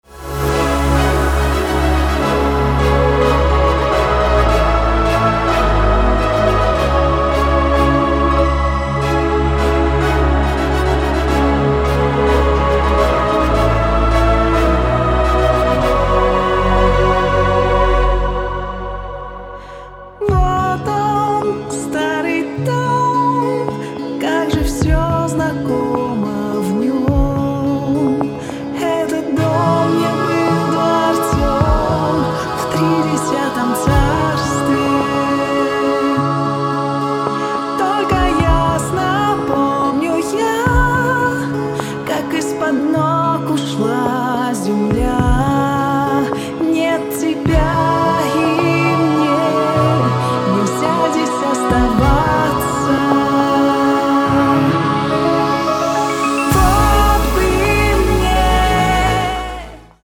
Chillout